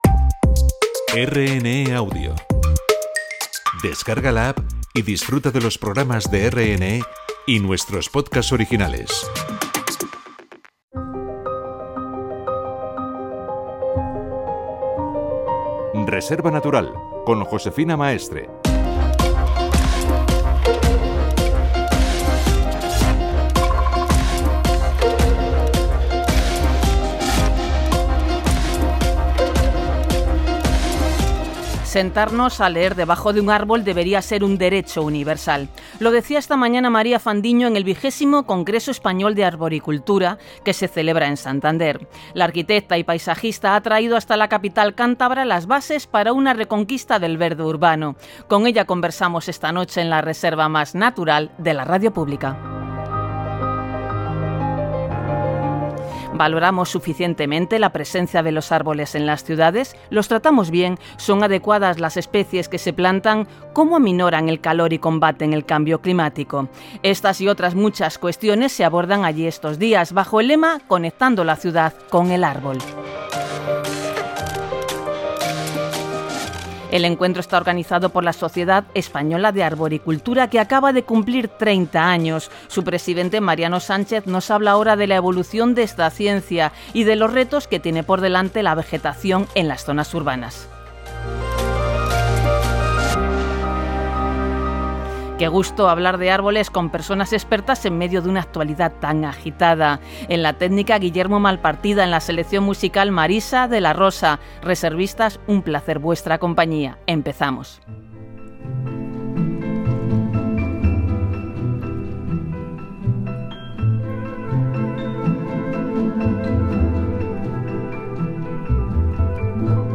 Programa de Radio Nacional 'Reserva Natural' sobre el XX Congreso y más...